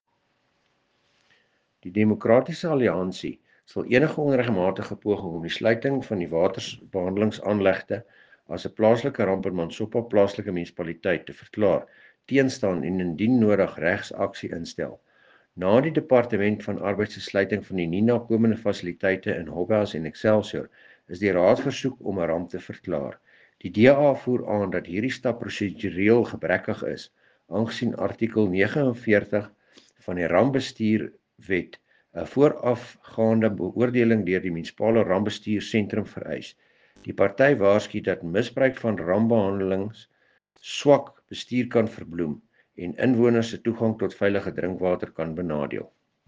Afrikaans soundbite by Cllr Dewald Hattingh